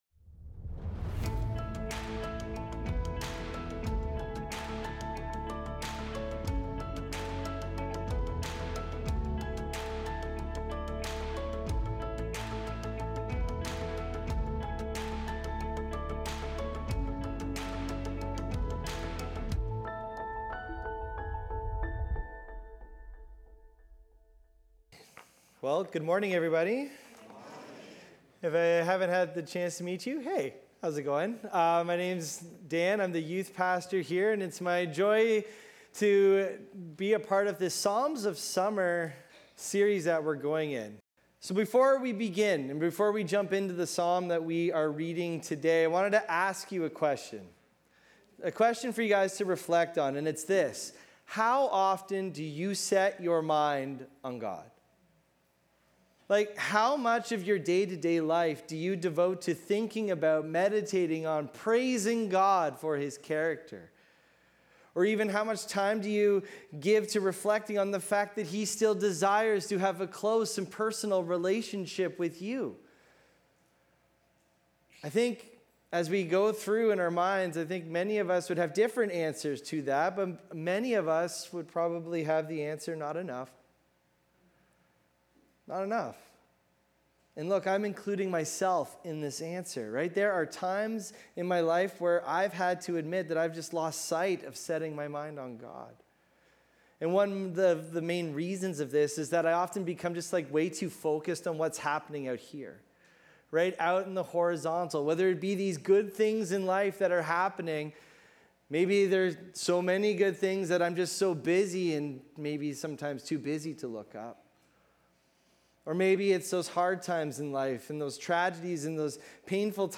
Recorded Sunday, July 20, 2025, at Trentside Fenelon Falls.